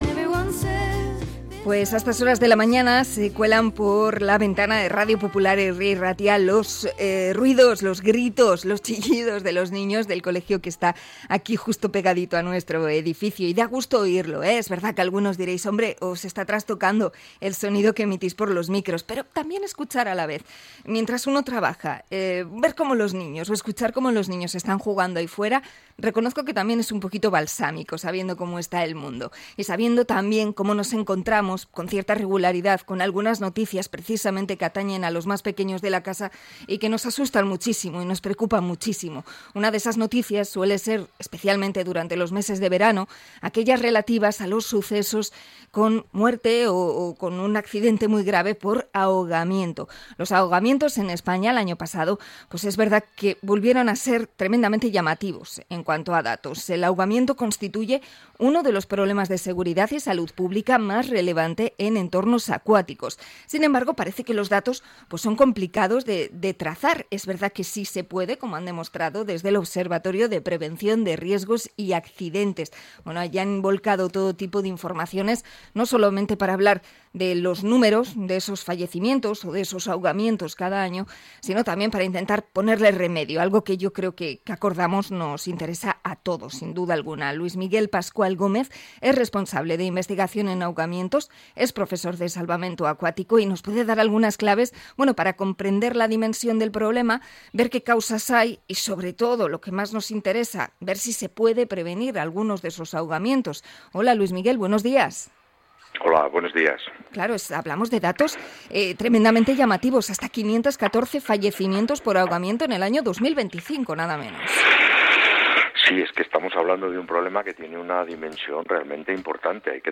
Entrevista a experto en salvamento marítimo